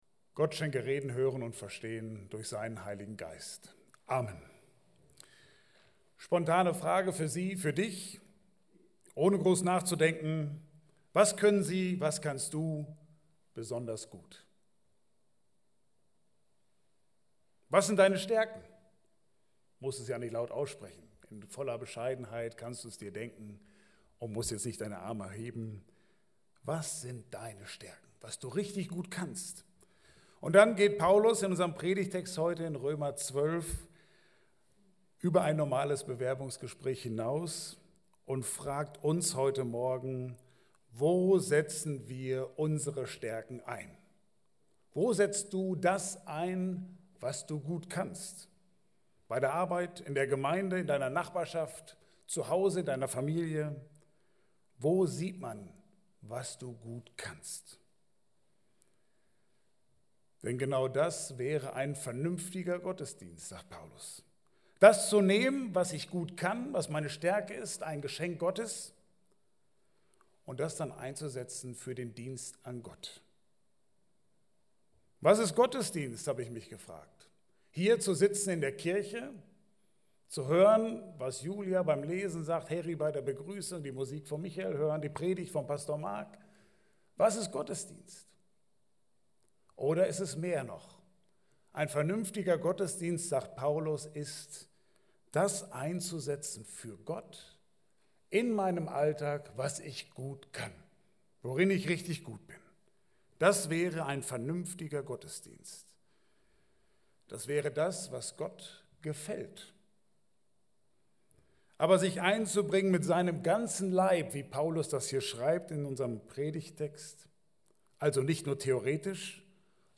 Passage: Römer 12, 1-8 Dienstart: Gottesdienst « Jesus